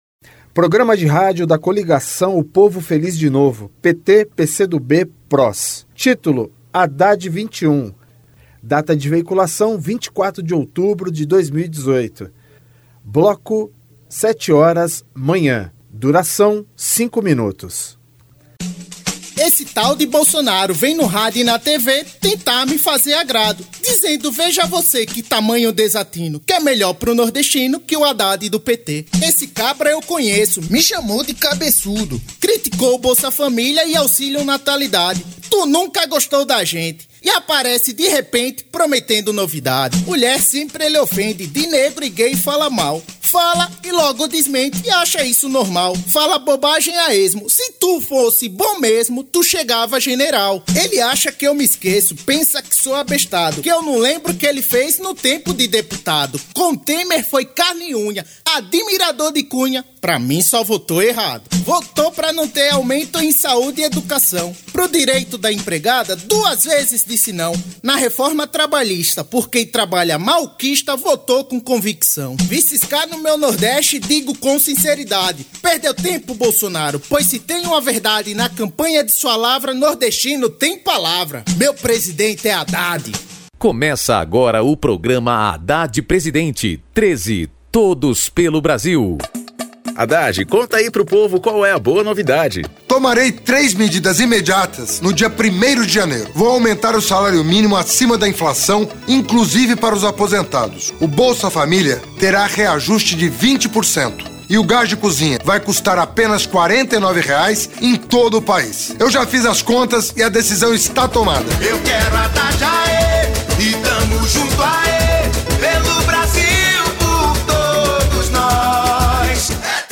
TítuloPrograma de rádio da campanha de 2018 (edição 51)
Descrição Programa de rádio da campanha de 2018 (edição 51), 2º Turno, 24/10/2018, bloco 7hrs.